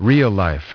Transcription and pronunciation of the word "real-life" in British and American variants.